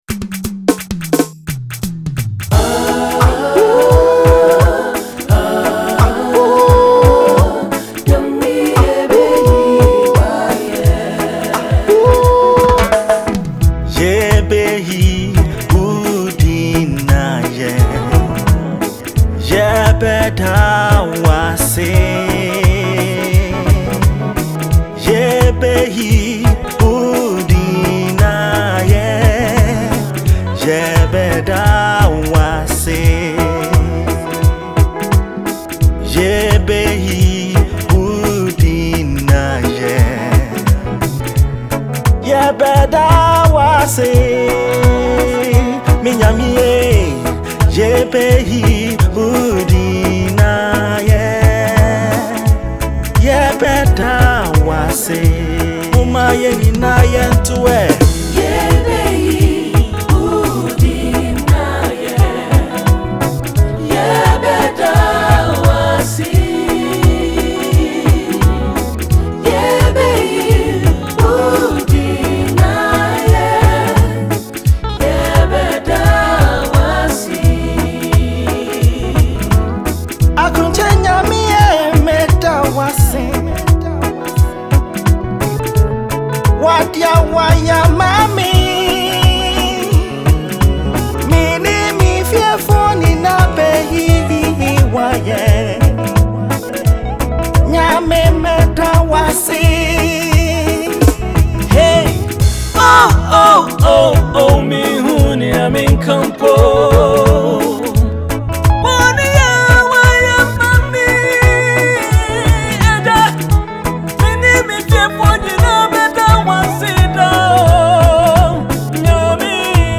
Gospel Musician
Gospel-reggae song